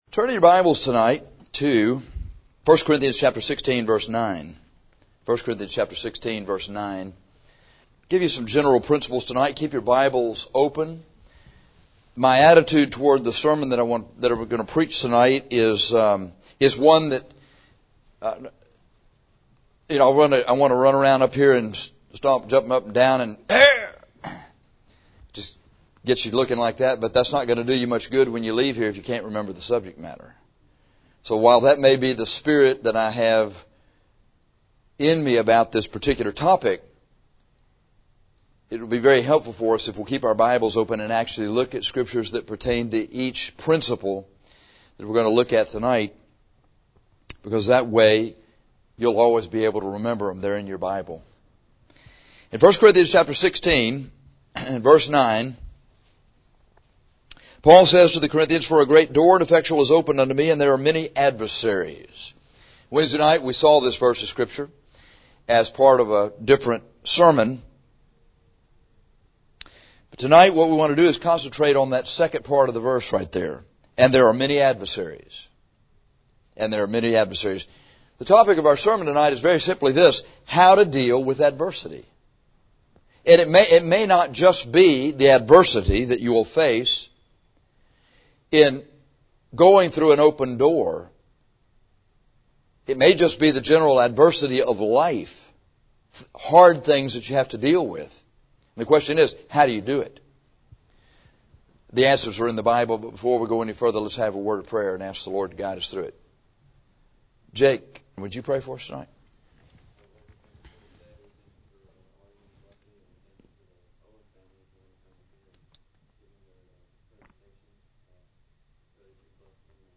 This sermon is on how to deal with adversity. When you are where God wants you to be, you will often face adversity; there will be struggles.